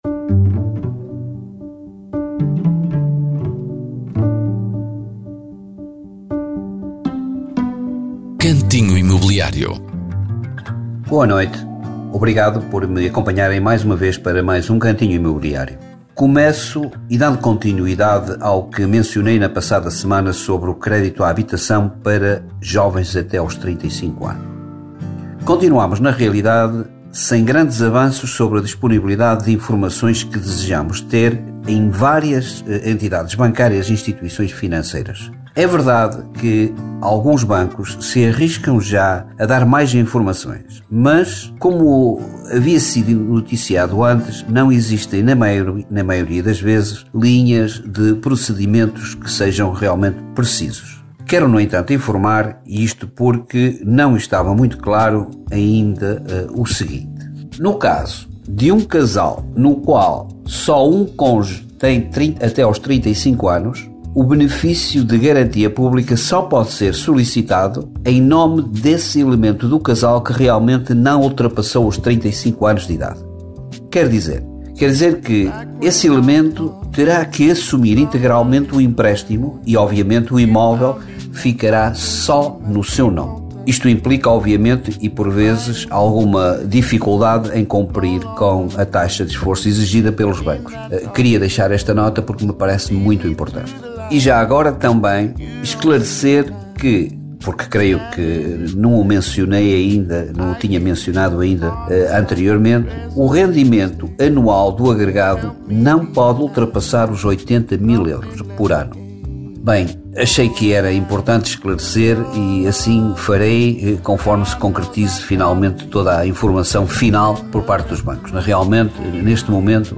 Cantinho Imobiliário é uma rubrica semanal da Rádio Caminha sobre o mercado imobiliário.